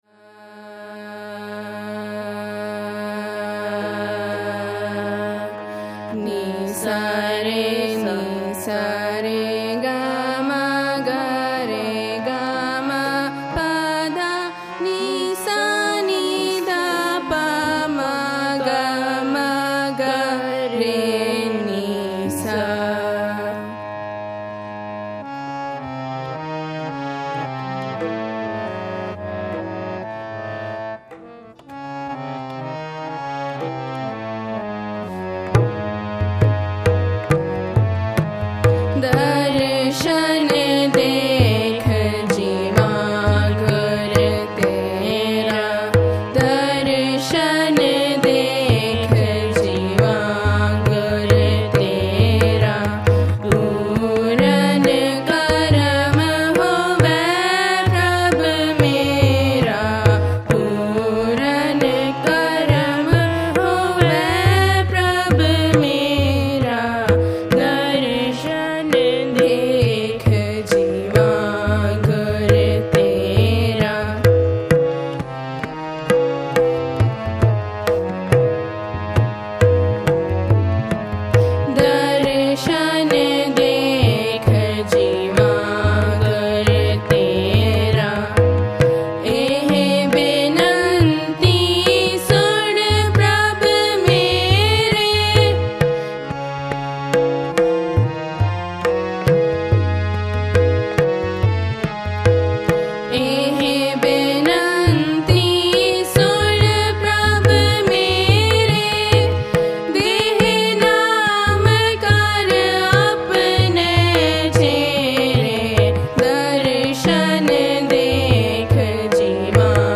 High Definition recordings of contemporary Gurmat Sangeet
at Scarborough Gurdwara on May 21 2011